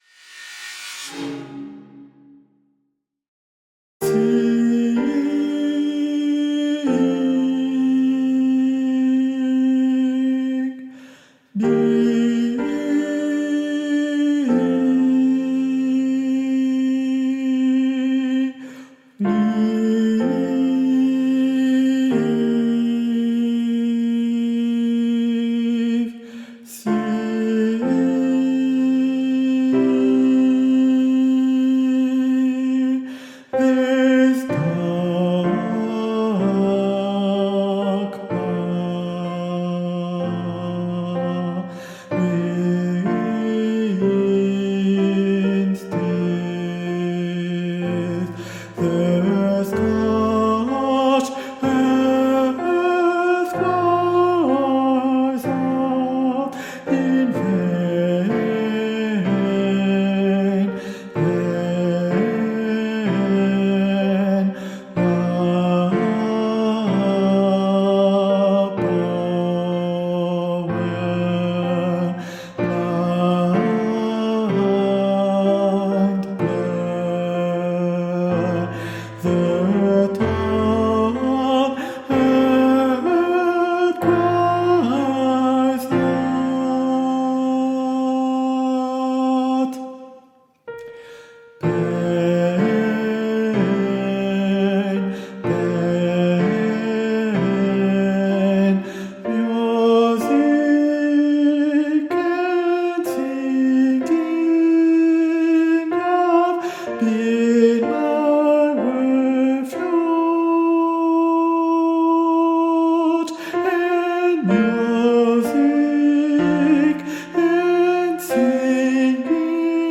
- Chant a capella à 4 voix mixtes SATB
Guide Voix Tenors